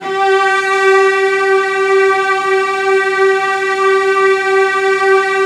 CELLOS AN4-R.wav